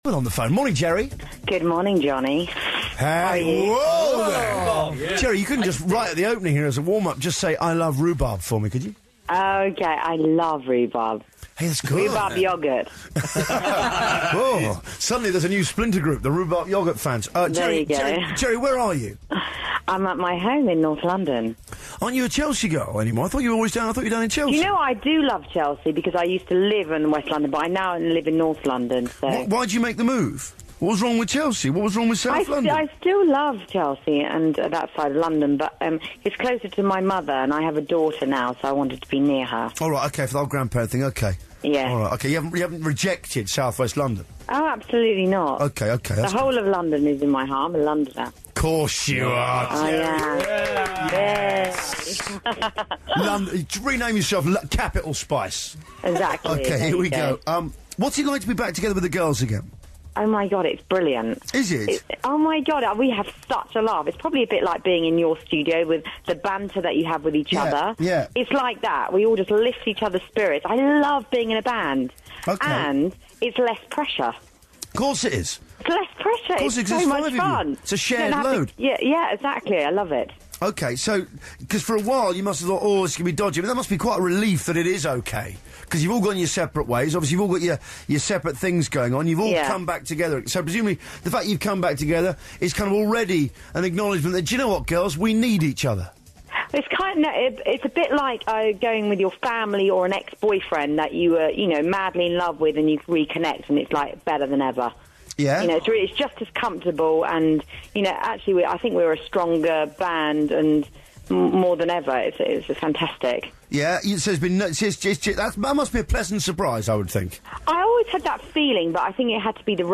Victoria & Geri Radio Interviews
Both Geri and Victoria called into radio shows today to promote the new Spice Girls single, "Headlines".